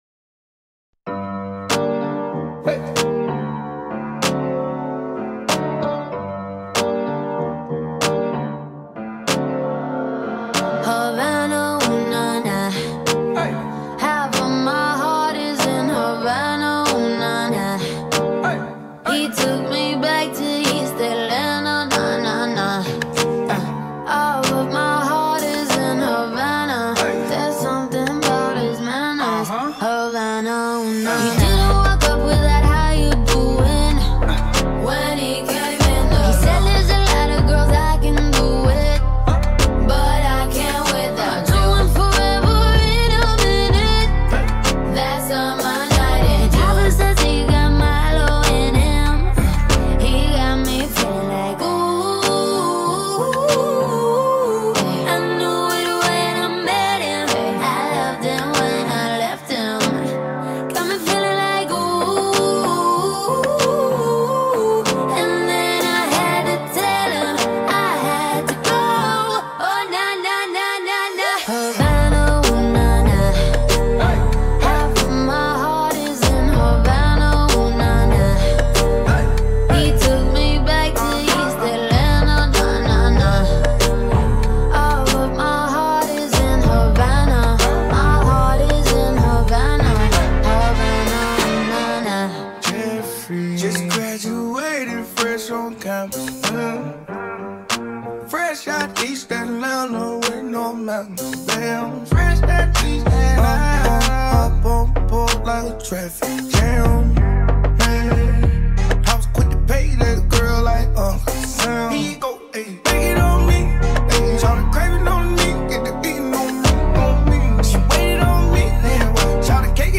Zouk R&B